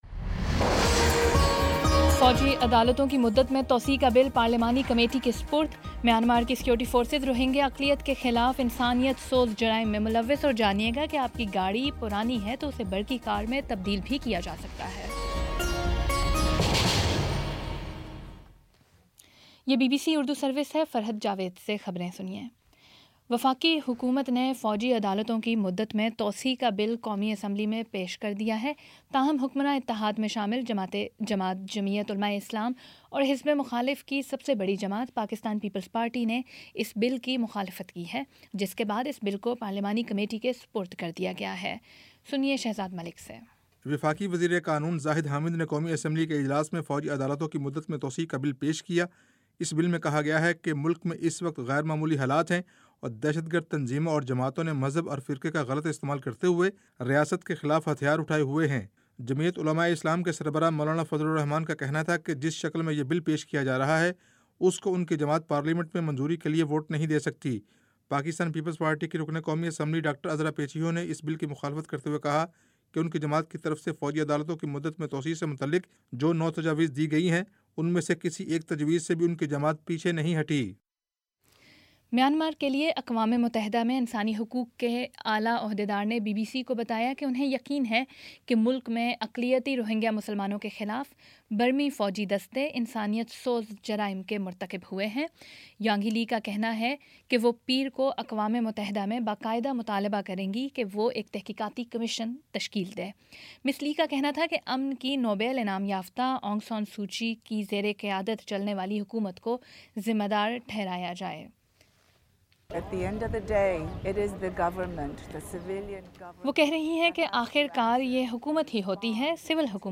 مارچ 10 : شام چھ بجے کا نیوز بُلیٹن